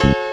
GUnit Key n bass.wav